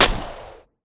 etfx_shoot_frost.wav